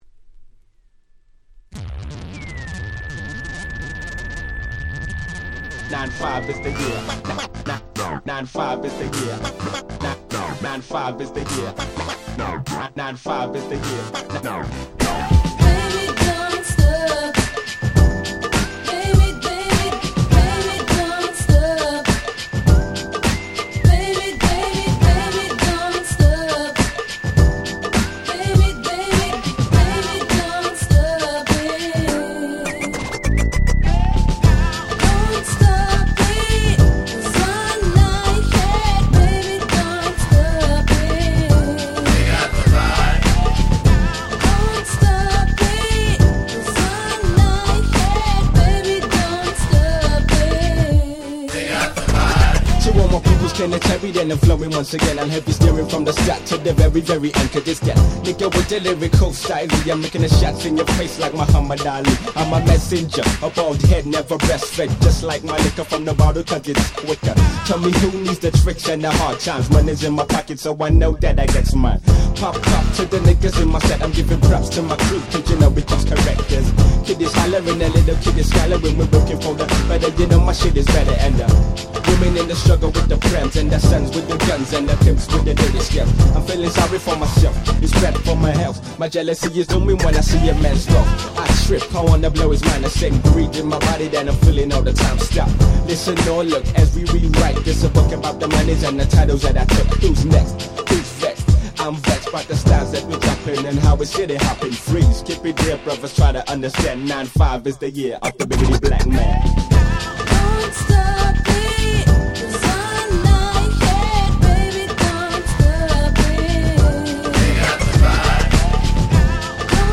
サビで女性Vocalも絡む大変キャッチーなユーロ歌ラップ物！！
Euro歌Rap最高峰。
ユーロ キャッチー系